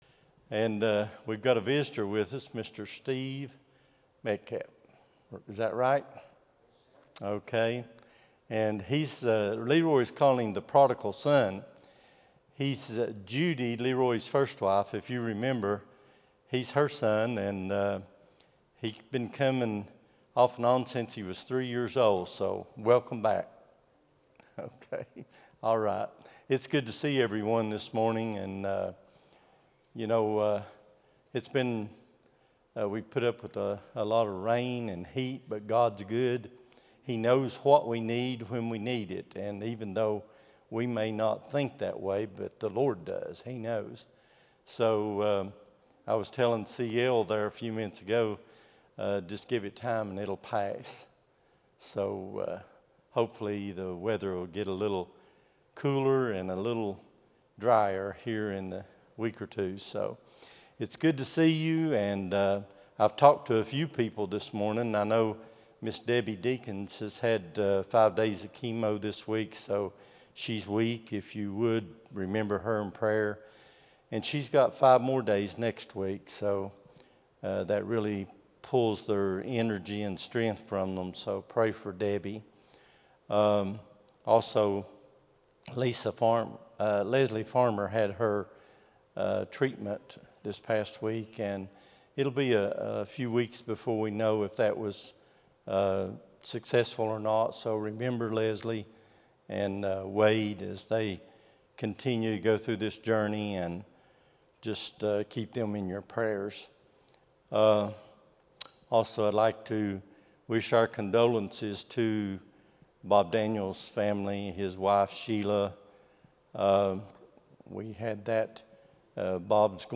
07-20-25 Sunday School | Buffalo Ridge Baptist Church